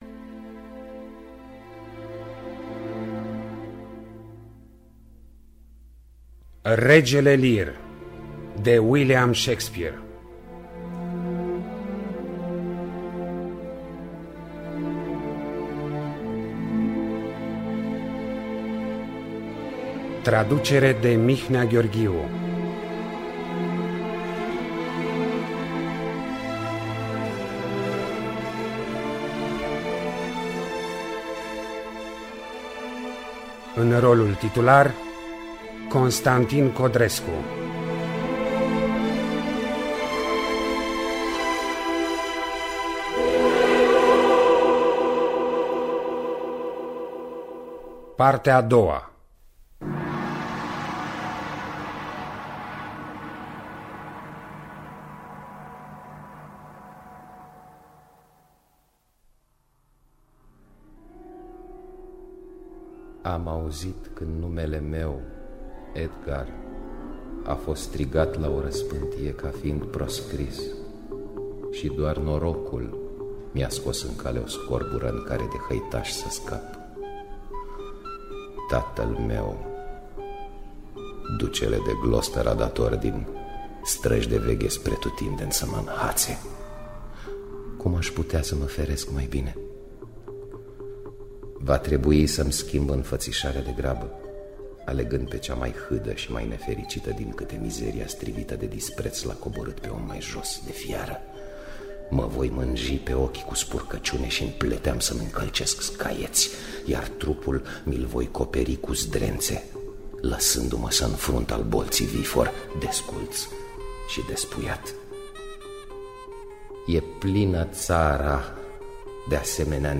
William Shakespeare – Regele Lear (2002) – Partea 2 – Teatru Radiofonic Online